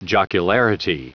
Prononciation du mot jocularity en anglais (fichier audio)
Prononciation du mot : jocularity